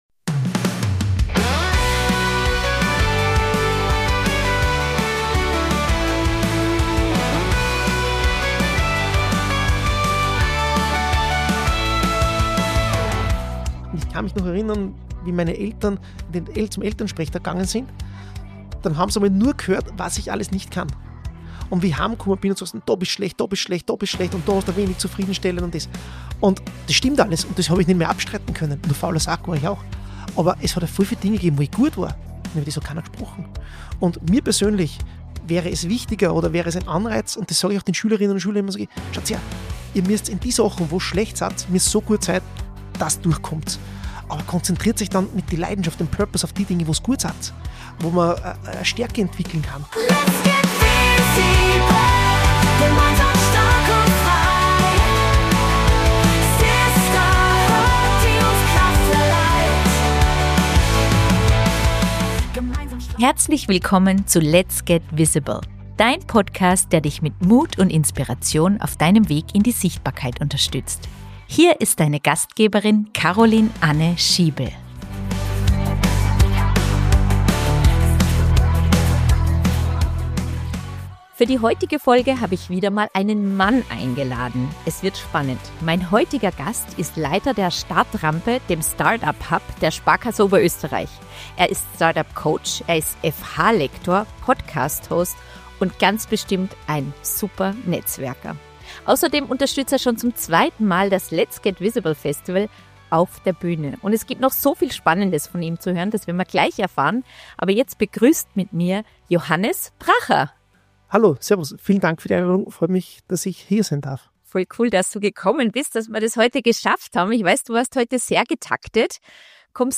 Dabei wird klar: Der Schlüssel liegt nicht in Perfektion, sondern im Fokus auf die eigenen Stärken – und im Vertrauen darauf, dass genau diese den Unterschied machen. Freut euch auf ein inspirierendes, humorvolles Gespräch über Mut, Scheitern, persönliche Entwicklung und Sichtbarkeit – mit vielen praktischen Einblicken und ehrlichen Anekdoten.